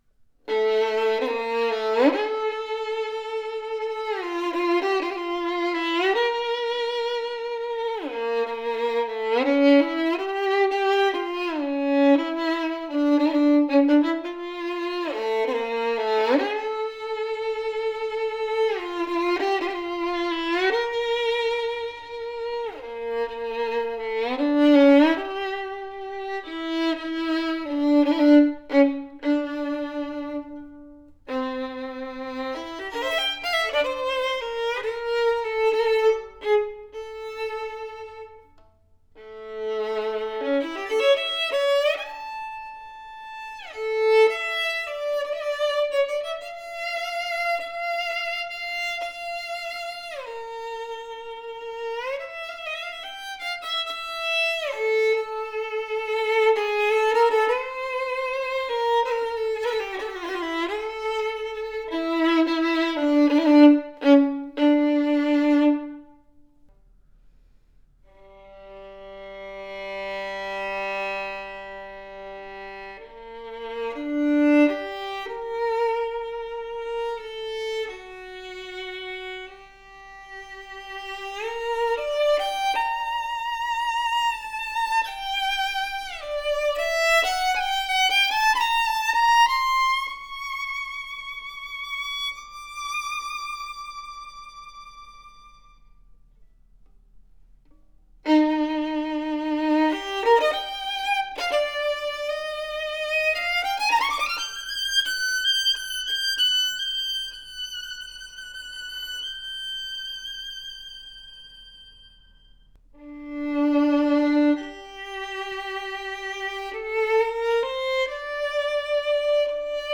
PHENOMENAL tone and workmanship on par with our more expensive violin!
A SUPERB powerful and deep sounding del Gesu due to its large and elongated f hole. Sonorous bold tone with fast response, robust and thick voice that sings with great projection!
new-studio-made-edition-guarneri-del-gesu-1744-beriot.wav